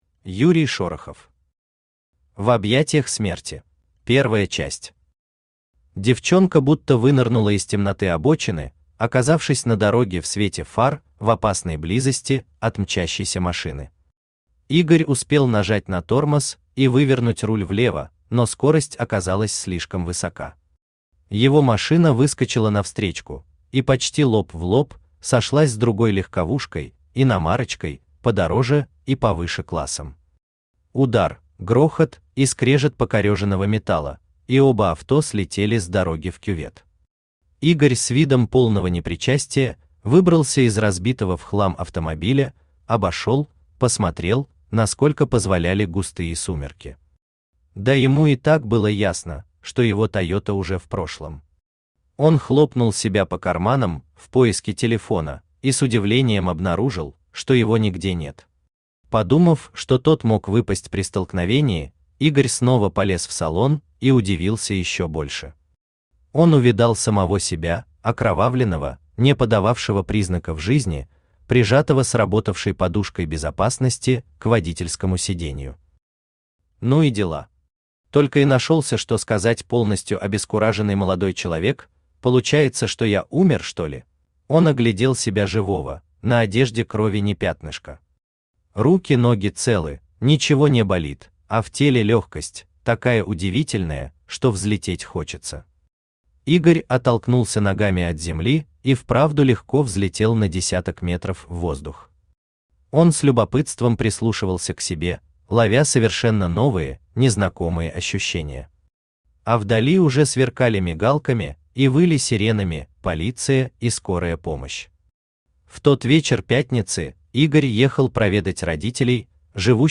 Аудиокнига В объятьях смерти | Библиотека аудиокниг
Читает аудиокнигу Авточтец ЛитРес.